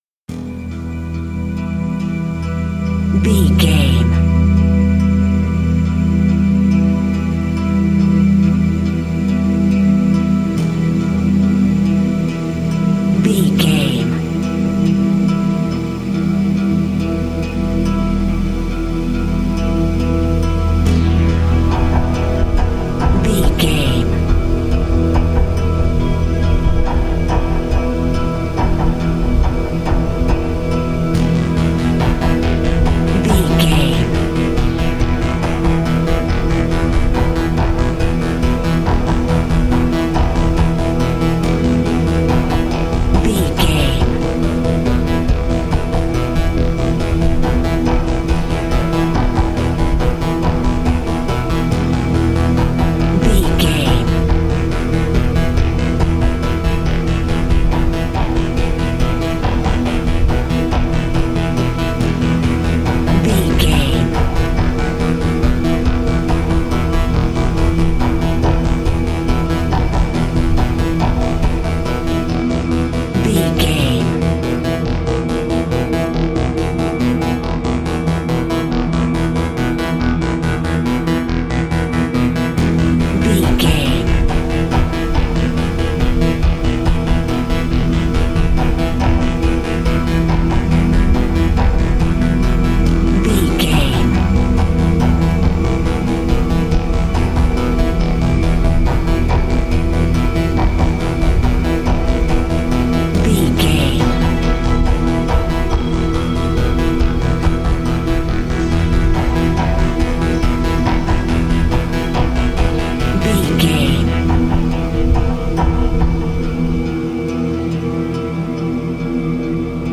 Aeolian/Minor
synthesiser
ominous
dark
suspense
haunting
creepy